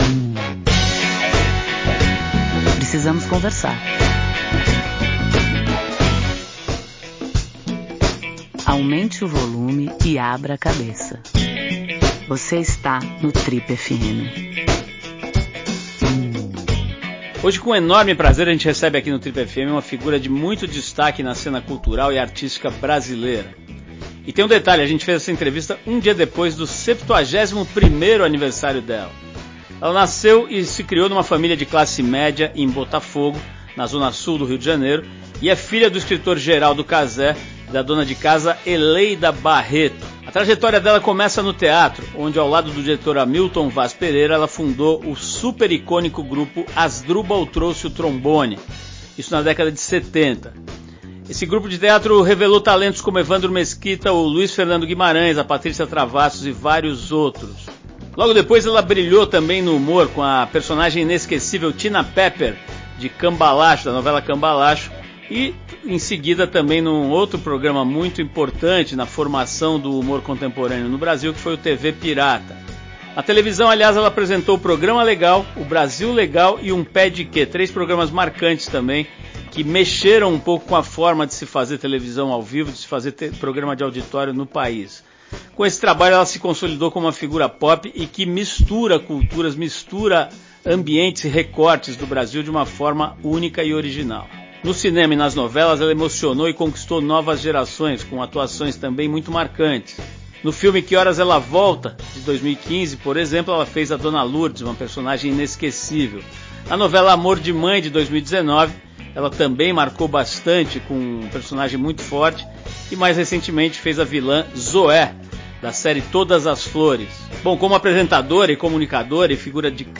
Uma das figuras mais admiradas e admiráveis do país, a atriz e apresentadora bate um papo sincero sobre família, religião, casamento e conta pra qual de seus tantos amigos ligaria de uma ilha deserta